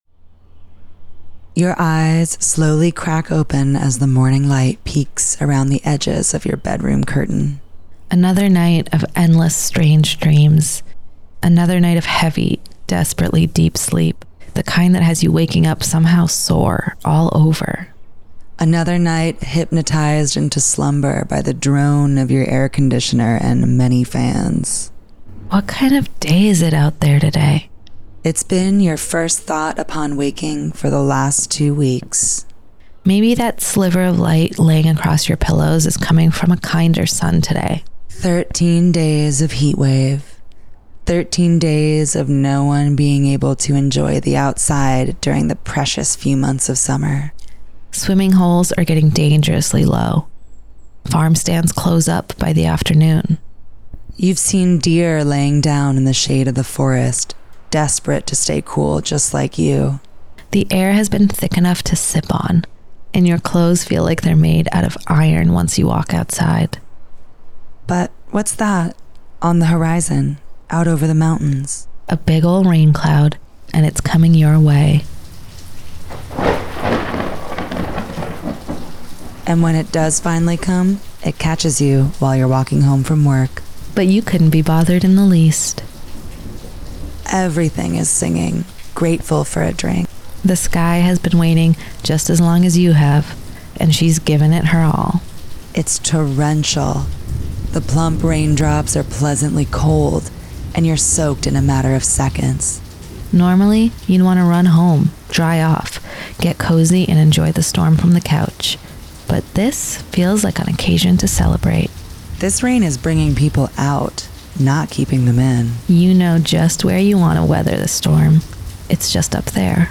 The Love Motel is a monthly radio romance talk show with love songs, relationship advice, and personals for all the lovers in the upper Hudson Valley.